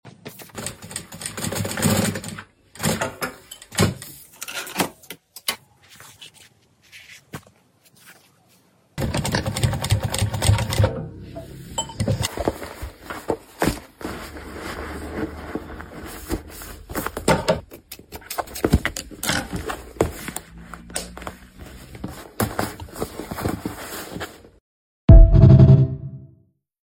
Amplificamos cómo suena el taller! sound effects free download